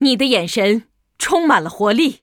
文件 文件历史 文件用途 全域文件用途 Lobato_amb_04.ogg （Ogg Vorbis声音文件，长度2.2秒，105 kbps，文件大小：29 KB） 文件说明 源地址:游戏语音 文件历史 点击某个日期/时间查看对应时刻的文件。 日期/时间 缩略图 大小 用户 备注 当前 2018年11月17日 (六) 03:34 2.2秒 （29 KB） 地下城与勇士  （ 留言 | 贡献 ） 分类:洛巴赫 分类:地下城与勇士 源地址:游戏语音 您不可以覆盖此文件。